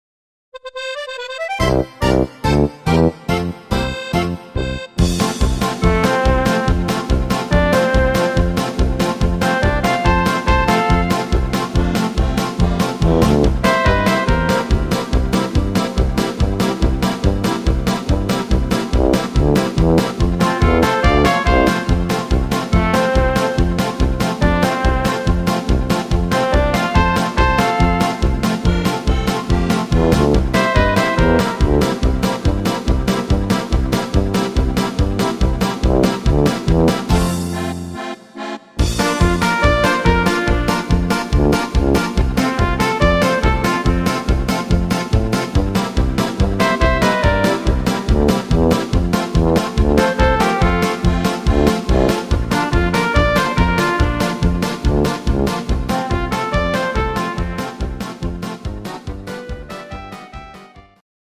Rhythmus  Polka
Art  Volkstümlich, Deutsch